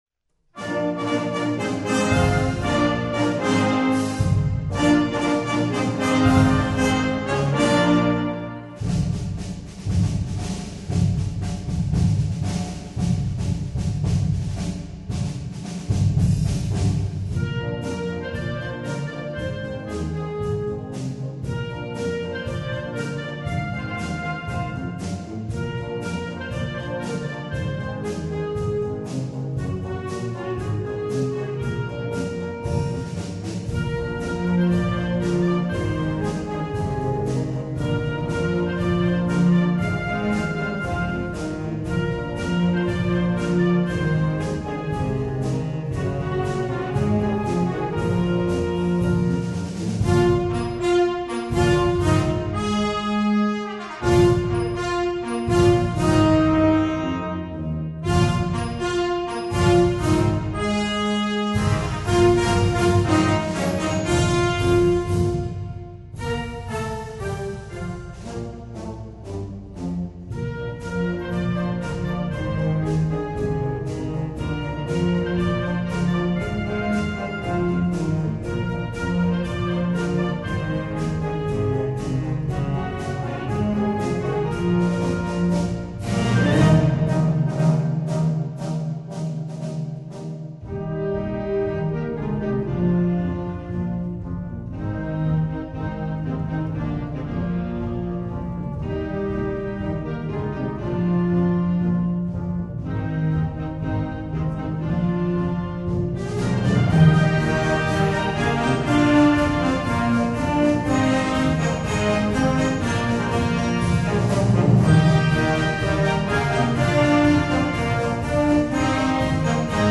Una brano fresco e accattivante.
MUSICA PER BANDA